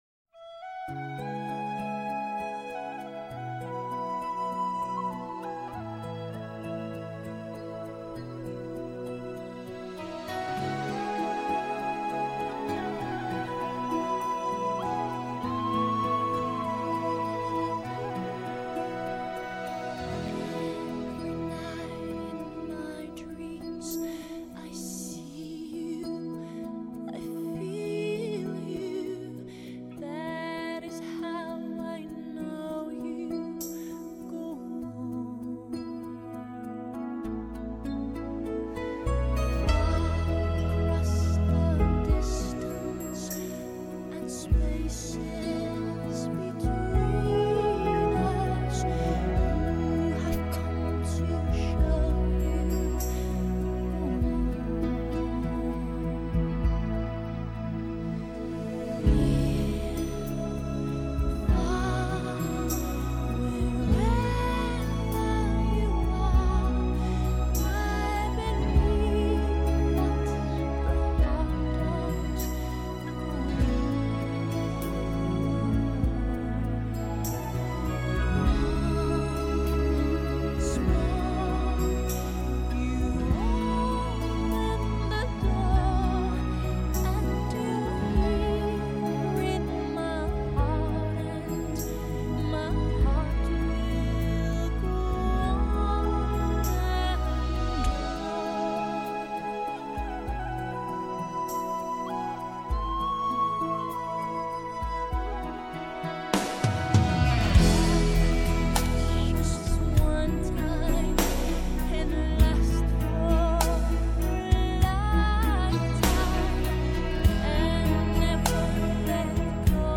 (Karaoke Version)